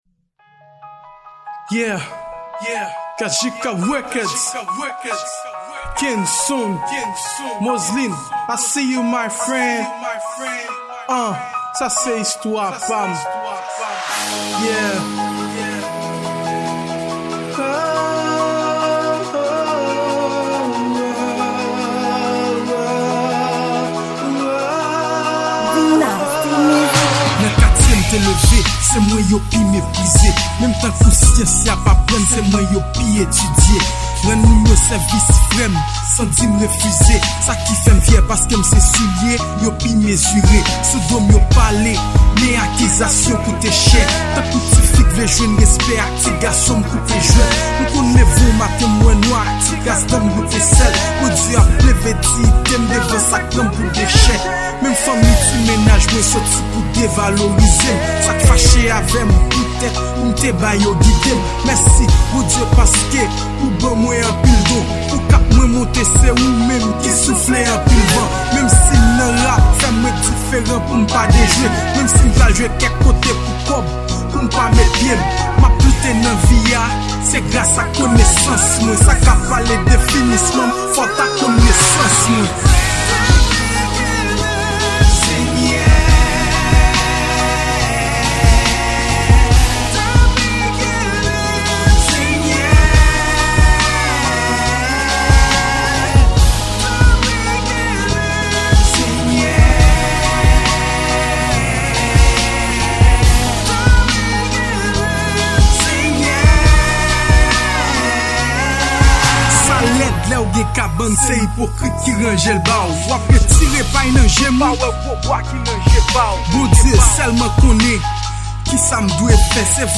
Genre: Rap Gospel